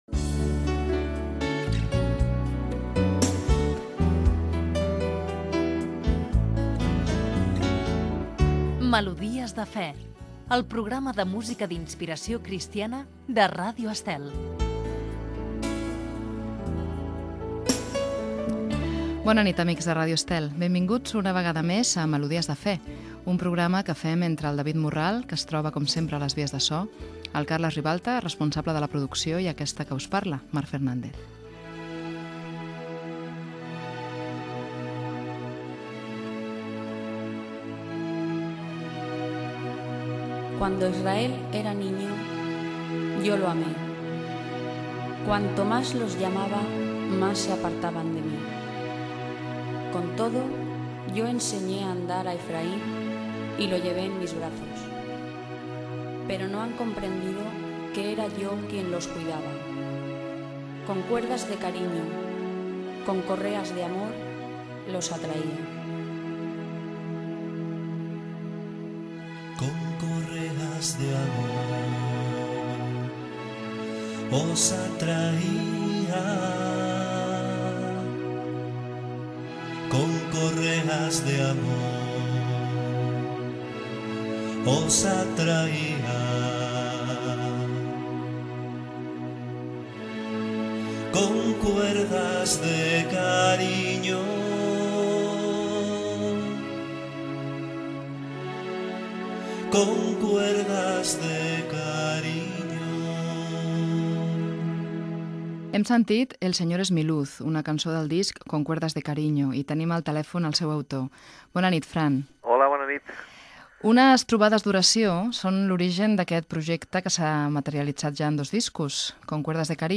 Careta del programa, presentació, equip, tema musical, entrevista
Gènere radiofònic Musical